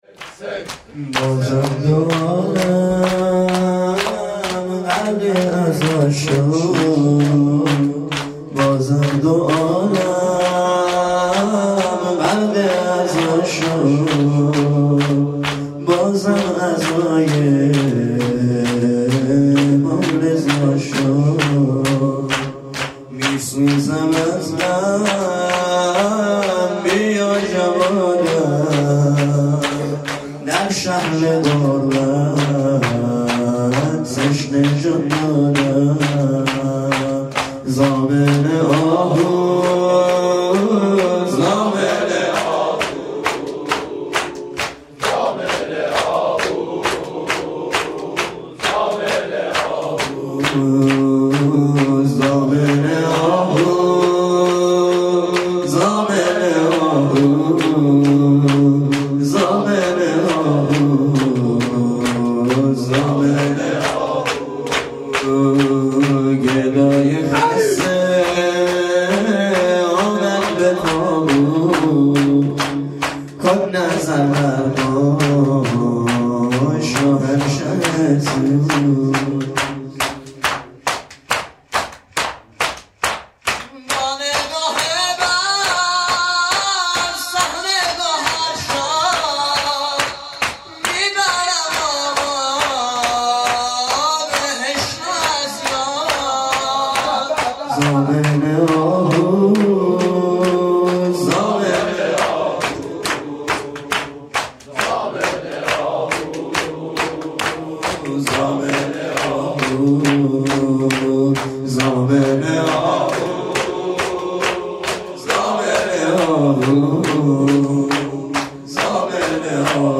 بازم دو عالم غرق عزا شد بازم عزای امام رضا شد ( شور )